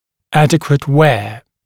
[‘ædɪkwət weə][‘эдикуэт уэа]правильное ношение (т.е. соответствующее требованиям)